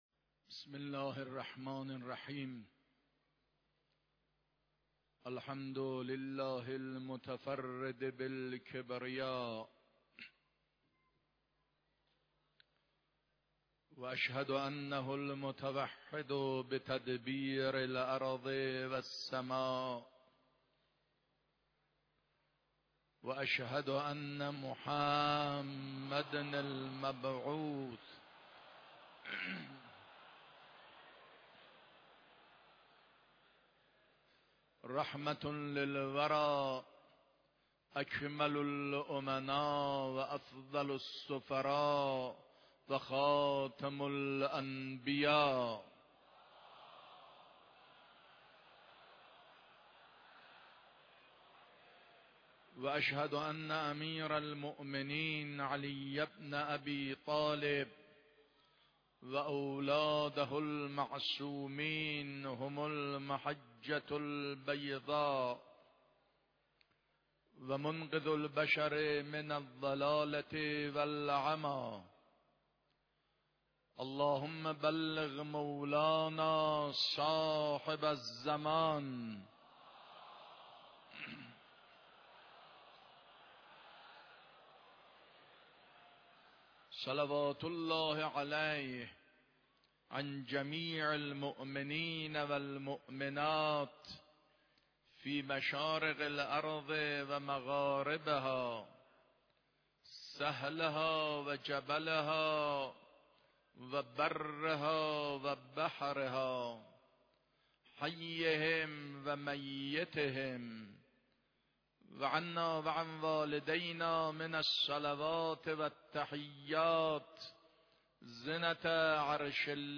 خطبه اول نمازجمعه 24 مرداد.mp3